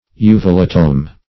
Search Result for " uvulatome" : The Collaborative International Dictionary of English v.0.48: Uvulatome \U"vu*la*tome\, n. [Uvula + Gr.